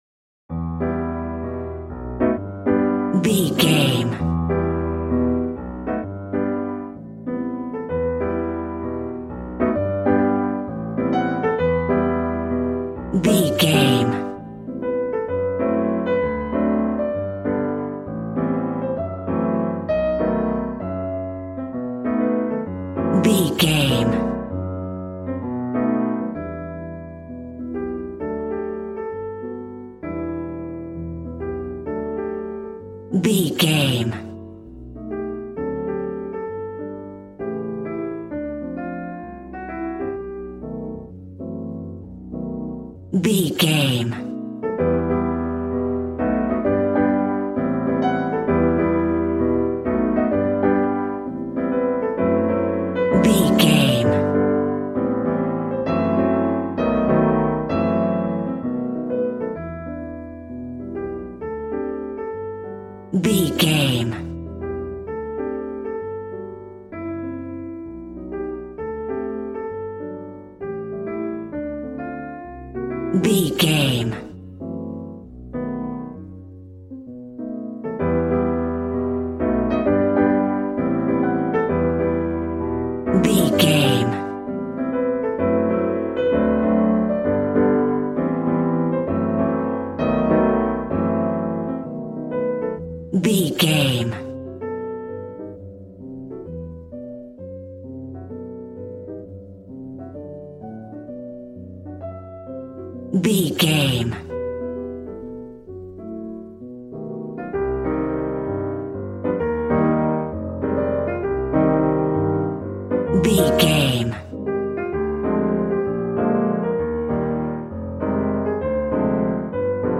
Smooth jazz piano mixed with jazz bass and cool jazz drums.,
Aeolian/Minor
piano
drums